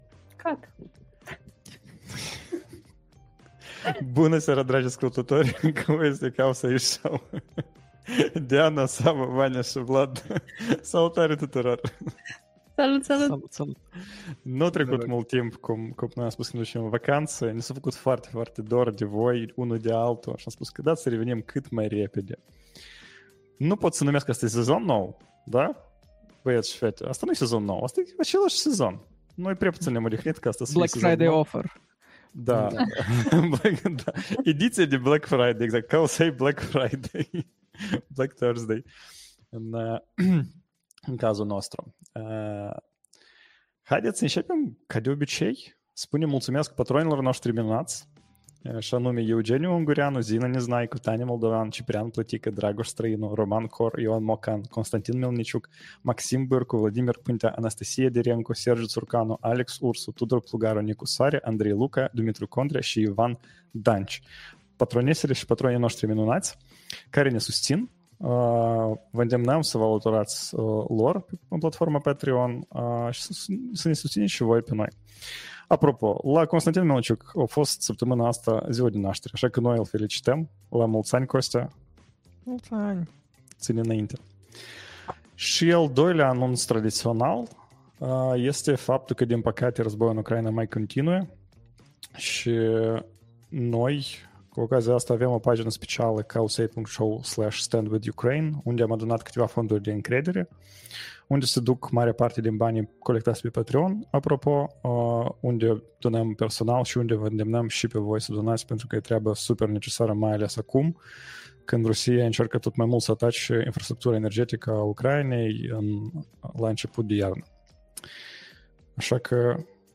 November 28th, 2024 Live-ul săptămânal Cowsay Show.